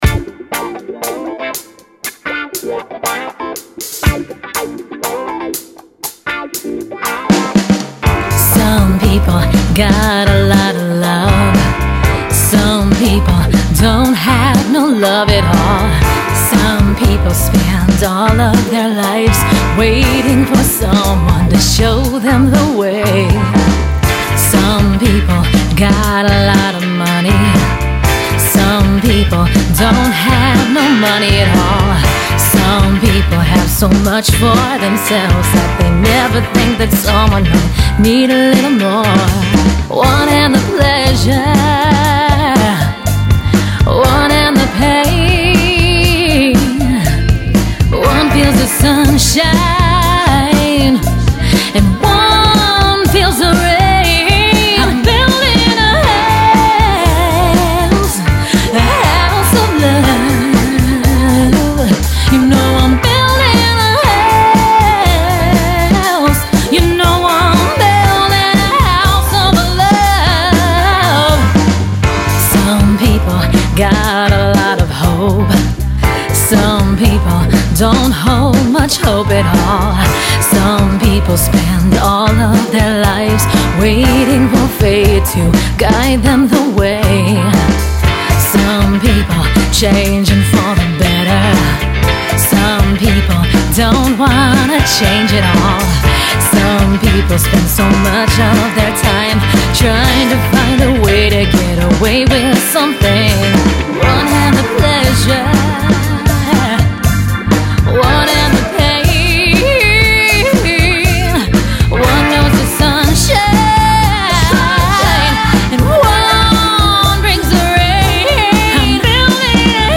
Genre Religious